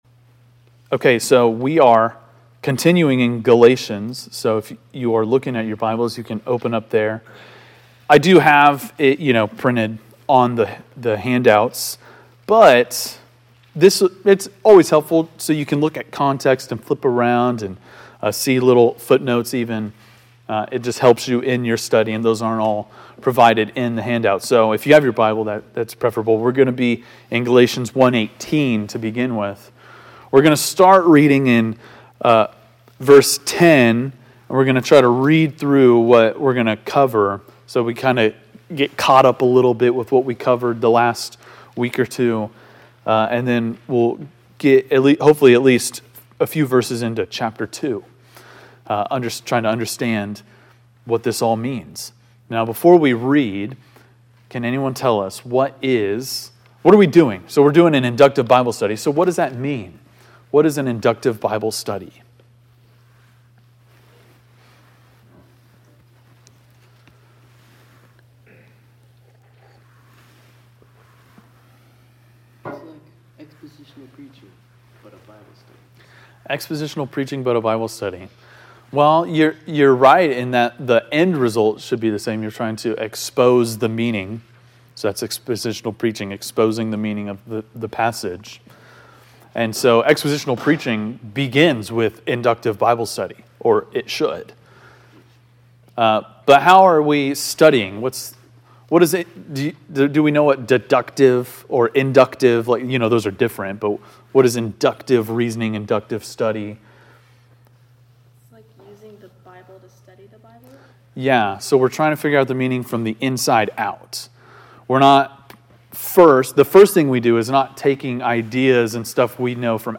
Galatians 1:18-2:5 (Inductive Bible Study)